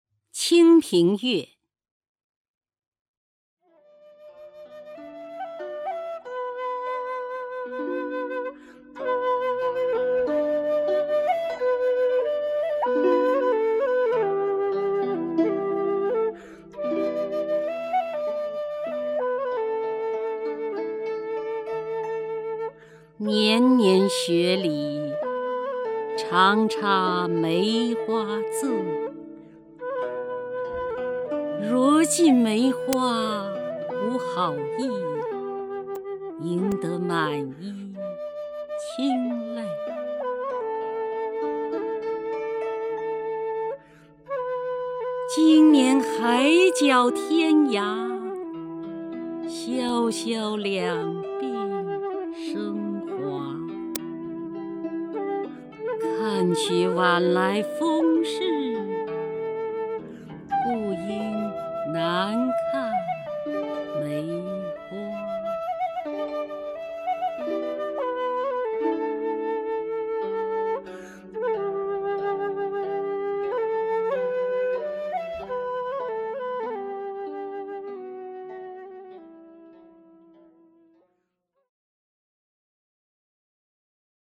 首页 视听 名家朗诵欣赏 姚锡娟
姚锡娟朗诵：《清平乐·年年雪里》(（南宋）李清照)　/ （南宋）李清照